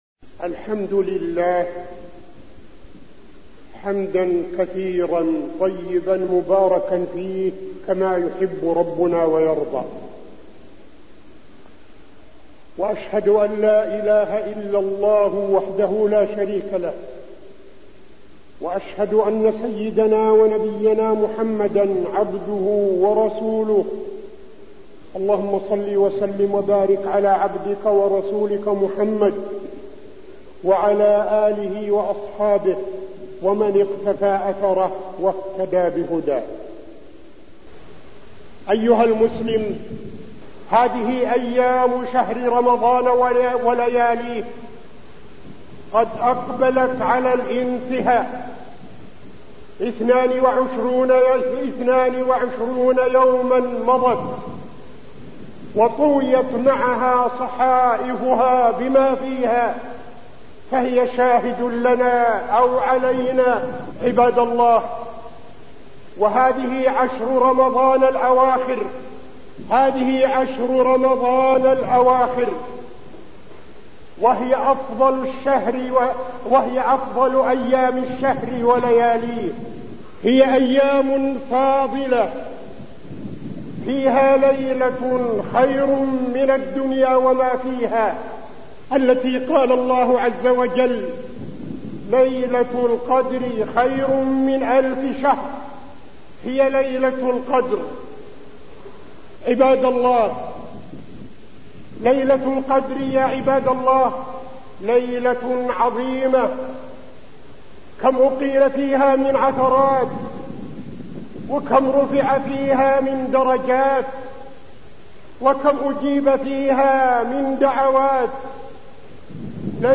تاريخ النشر ٢٢ رمضان ١٤٠٦ هـ المكان: المسجد النبوي الشيخ: فضيلة الشيخ عبدالعزيز بن صالح فضيلة الشيخ عبدالعزيز بن صالح فضل ليلة القدر